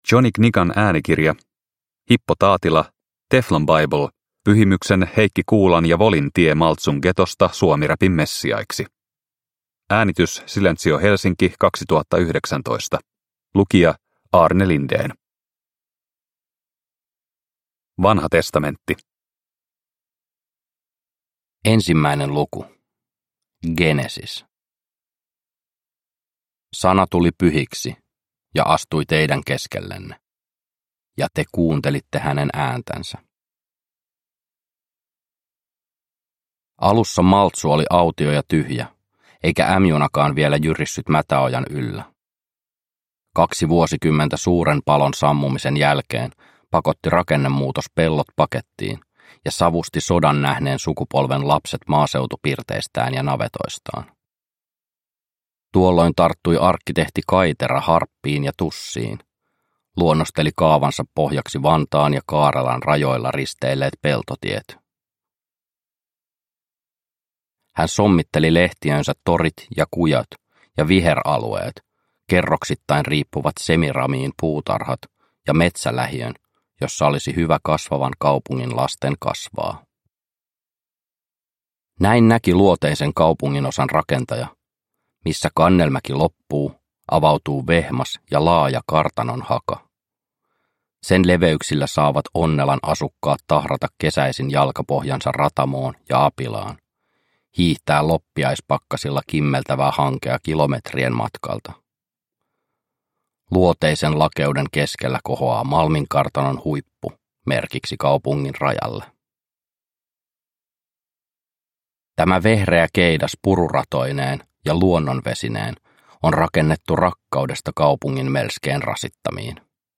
Teflon Bible – Ljudbok